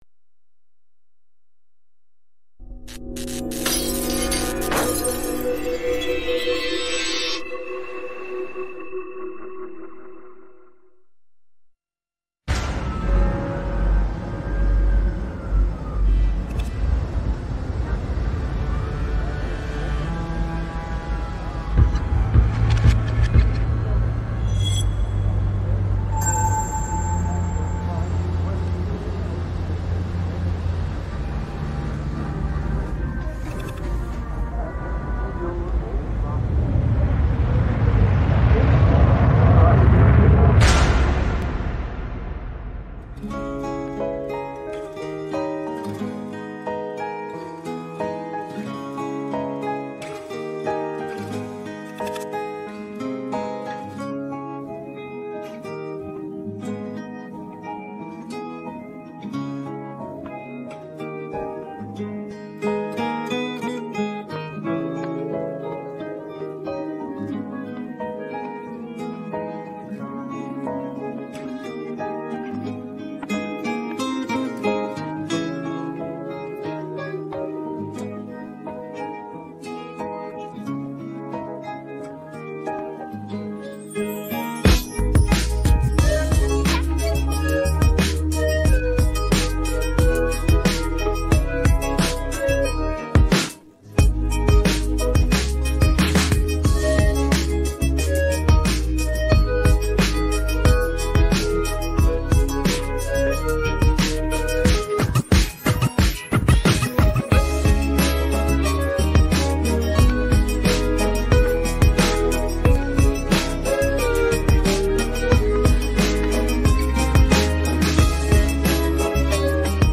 pop караоке 63
Українські хіти караоке